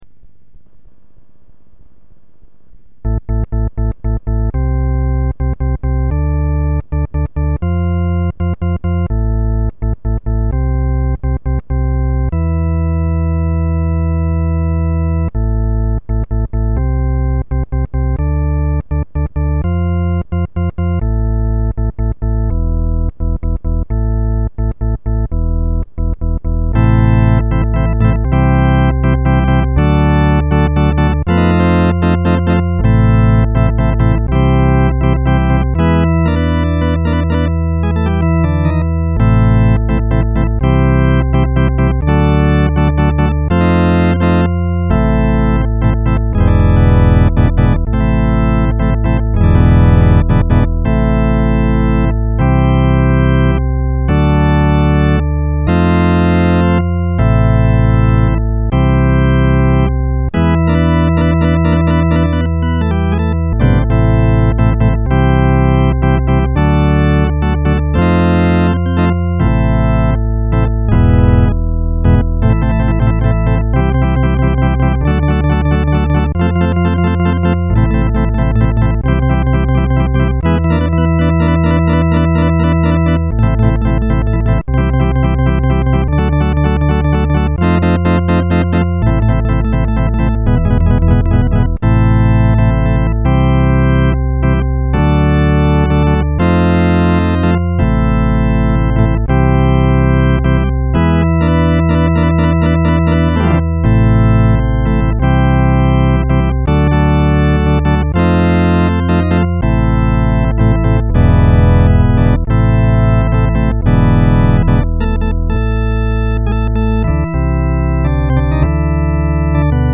lab Hammond XB 1